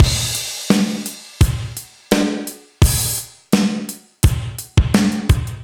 Index of /musicradar/80s-heat-samples/85bpm
AM_GateDrums_85-02.wav